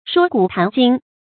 說古談今 注音： ㄕㄨㄛ ㄍㄨˇ ㄊㄢˊ ㄐㄧㄣ 讀音讀法： 意思解釋： 談說古今事。